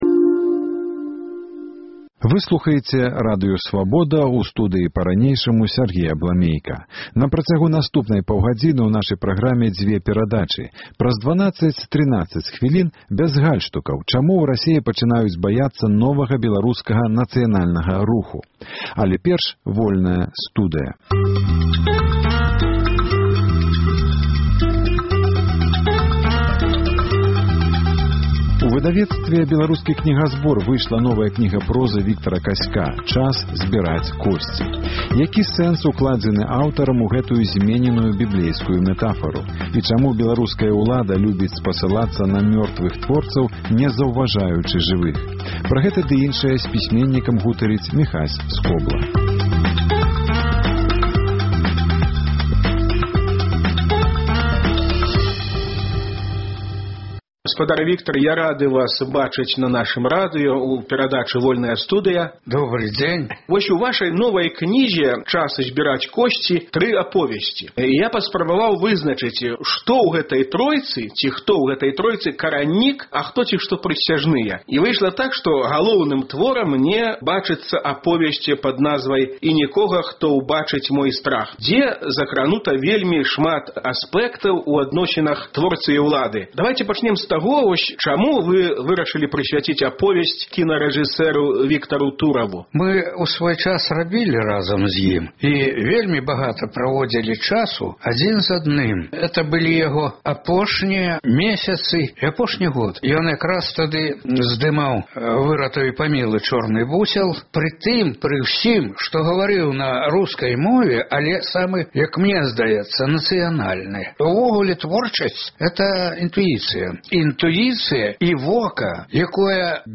Віктар Казько – госьцем перадачы.